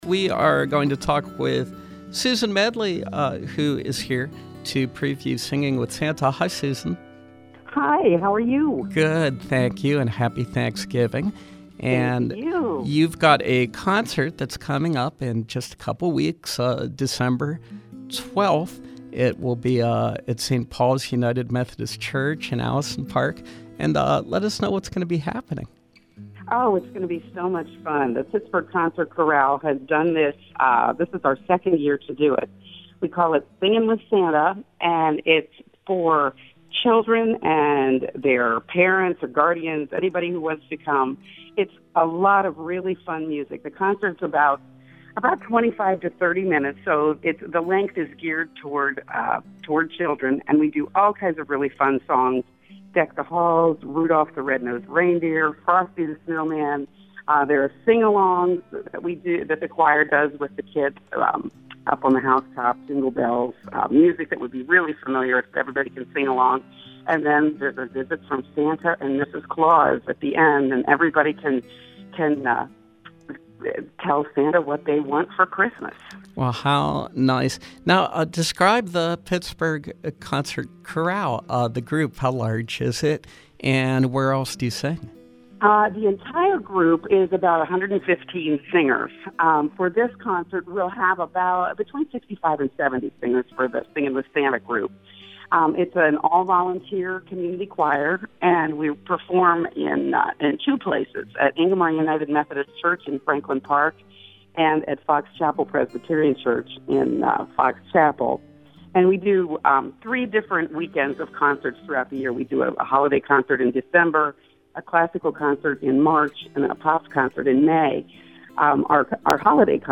Interview: Singin’ with Santa